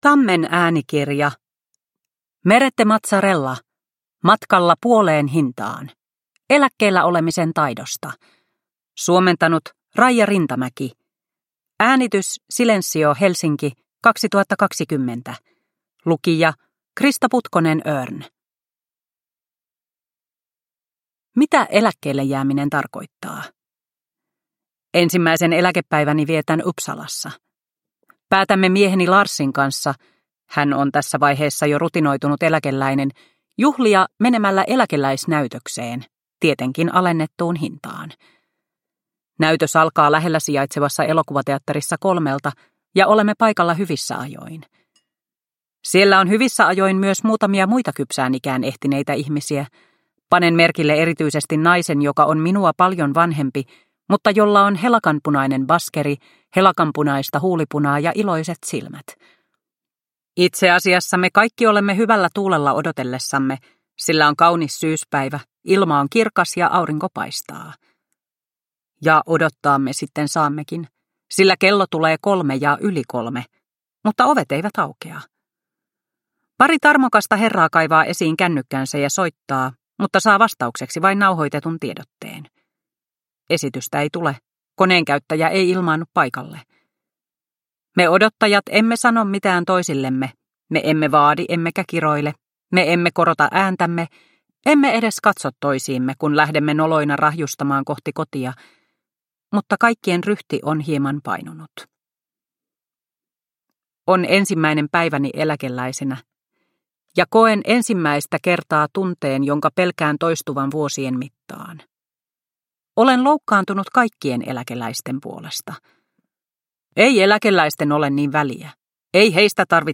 Matkalla puoleen hintaan – Ljudbok – Laddas ner